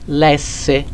La pronuncia indicata qui è quella standard; va ricordato, però che la pronuncia aperta o chiusa delle e e delle o varia da regione a regione.
é = e chiusa; è e aperta
lèsse verb S __ __ __ s/he read